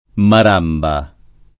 Maramba [ mar # mba ] top. (Zambia)